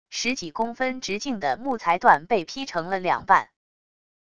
十几公分直径的木材段被劈成了两半wav音频